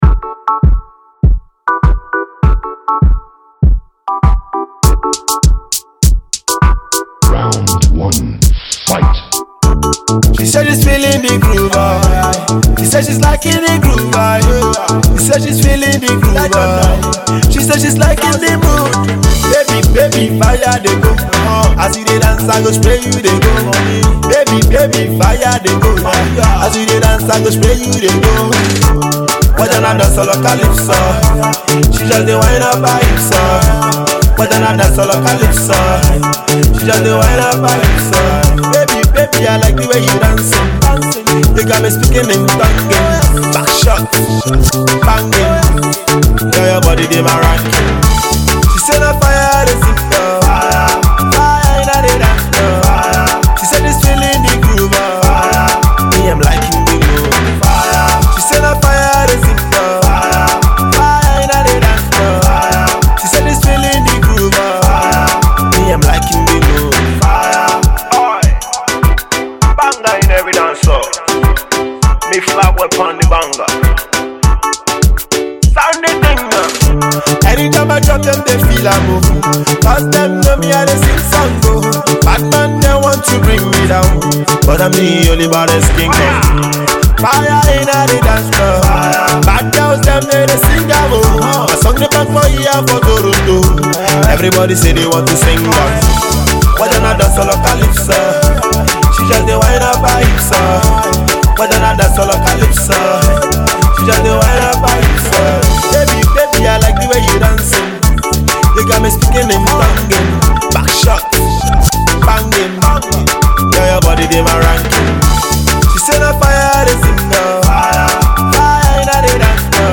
Dancehall
Galala/Konto influenced Single